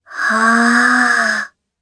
Kara-Vox_Casting4_jp.wav